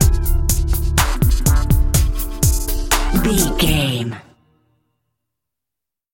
Aeolian/Minor
synthesiser
drum machine